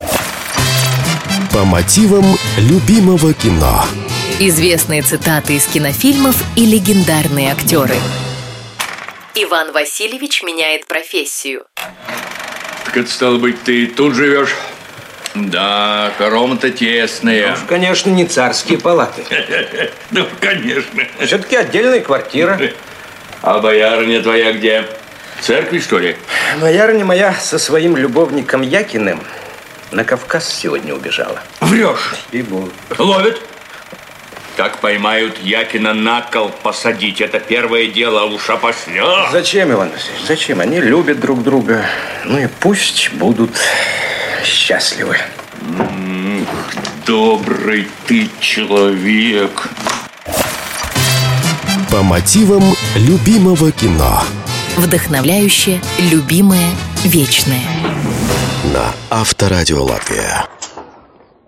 В этой программе вы услышите знаменитые цитаты из кинофильмов, озвученные голосами легендарных актеров.